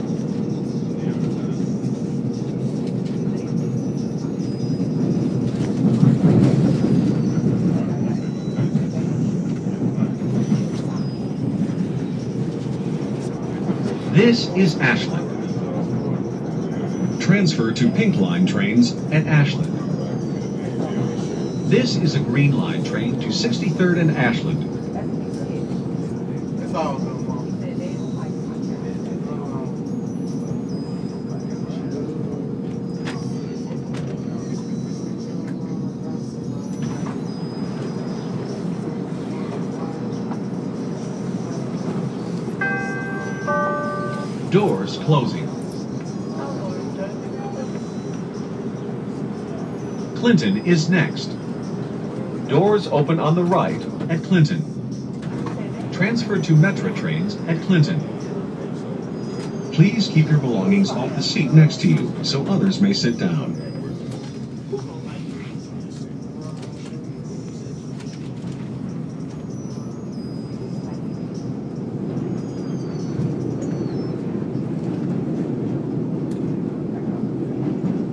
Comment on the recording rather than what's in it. Morning Green Line ride in to work